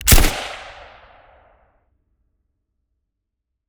lmg_shot.wav